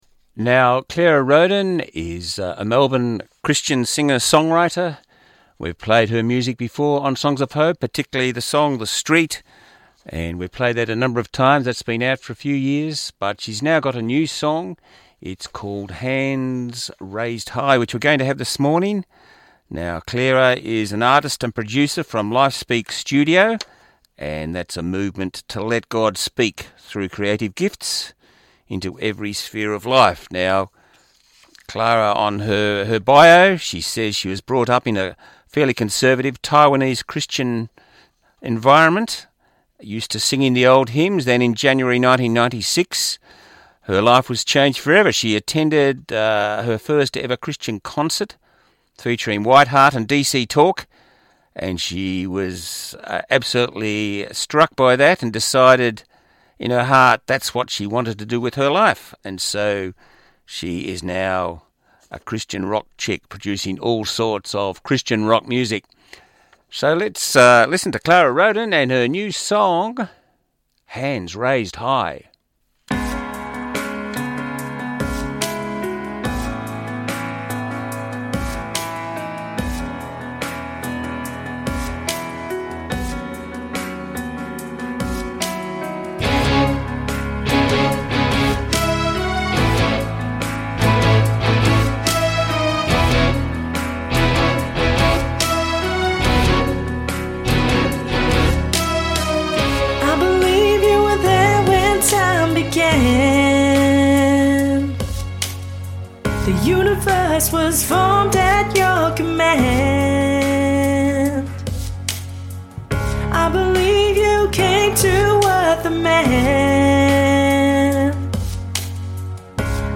a Melbourne based Christian artist and producer